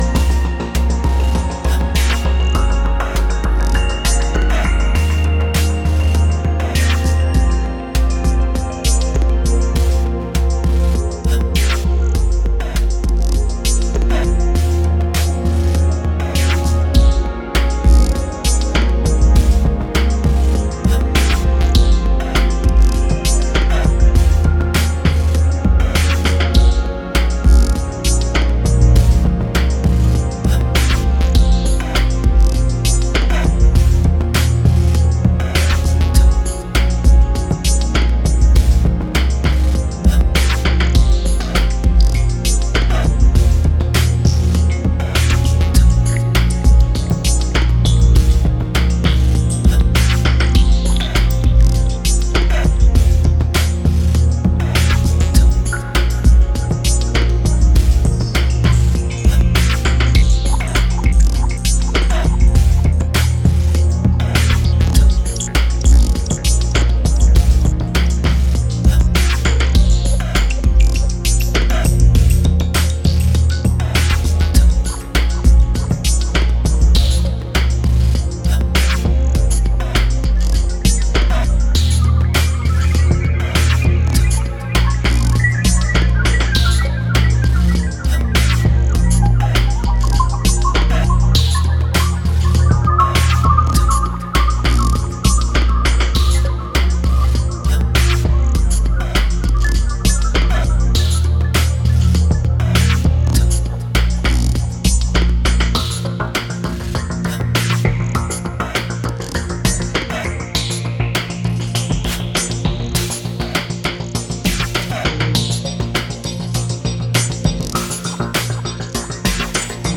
a downtempo beauty